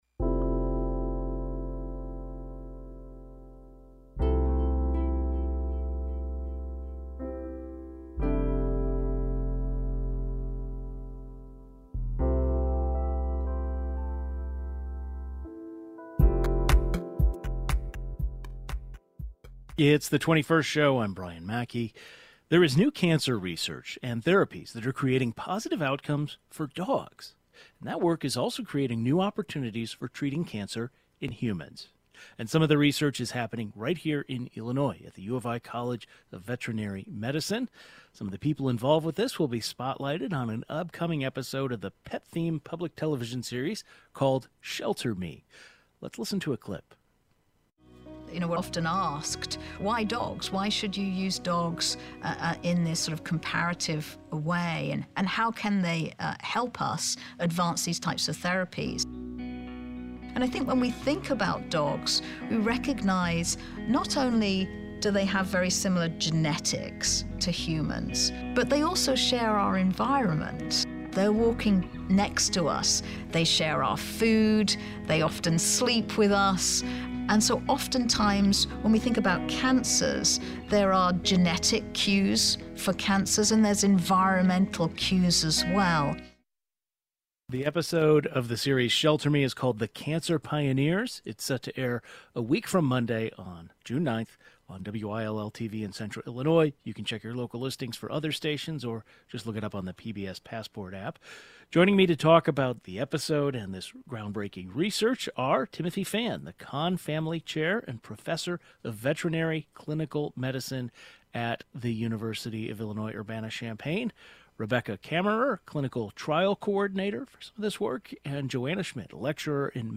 Oncology and veterinary experts from the university join the program to discuss the new research.